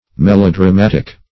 Melodramatic \Mel`o*dra*mat"ic\, a. [Cf. F. m['e]lodramatique.]